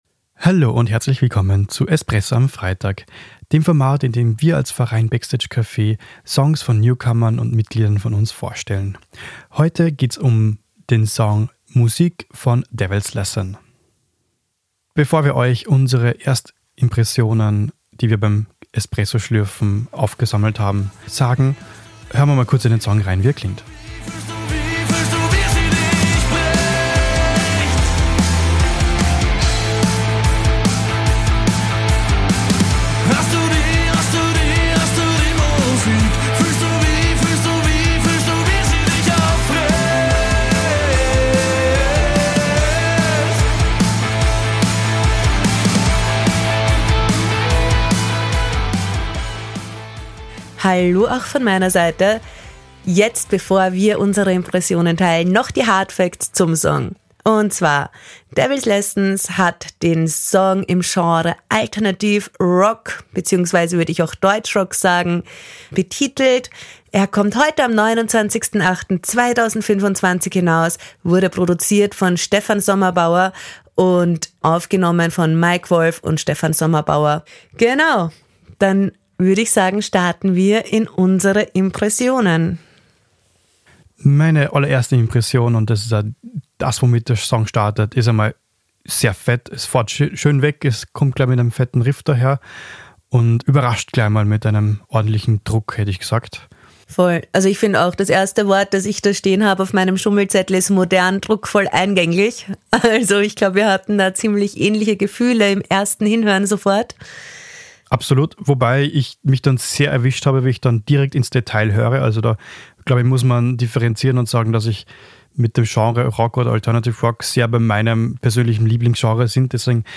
Die Release-Rezension für Newcomer